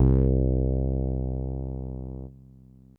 FRETLESS.wav